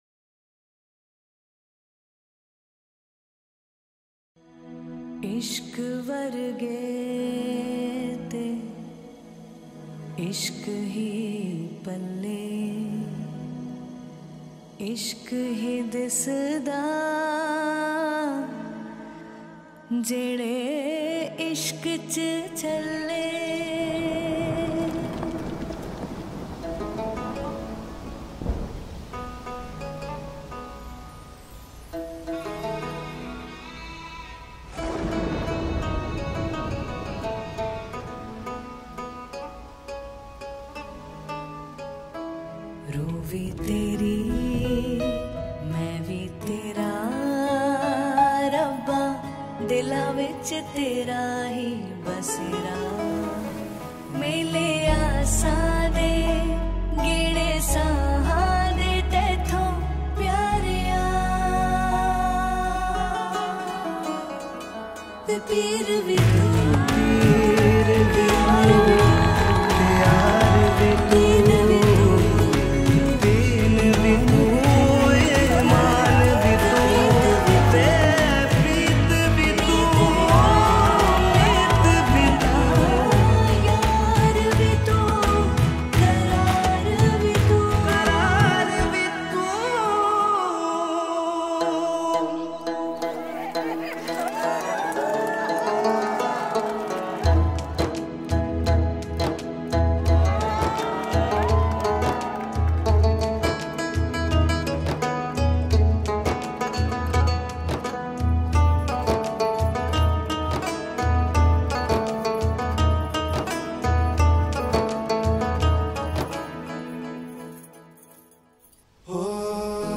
Category :Punjabi Music